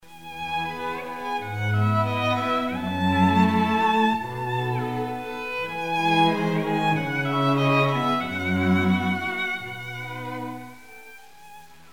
Репертуар дворца | Струнный квартет Нона